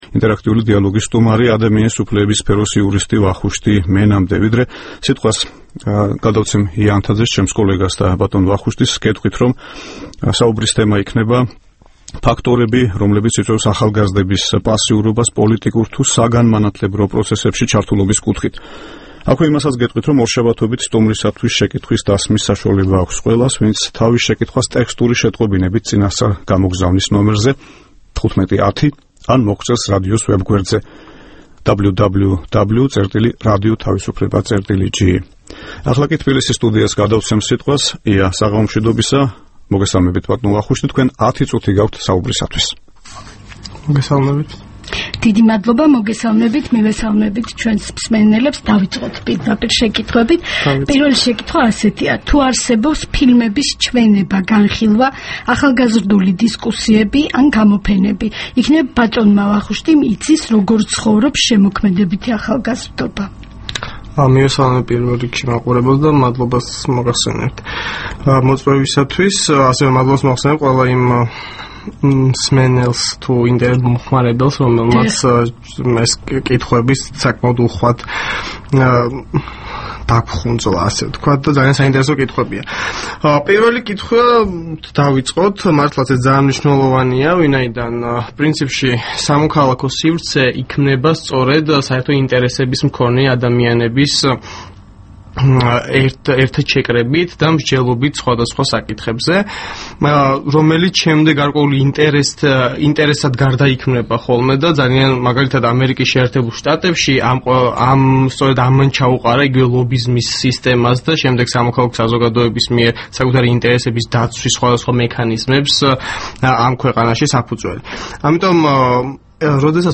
„ინტერაქტიული დიალოგის“ სტუმარი